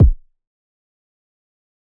Rack Kick7.wav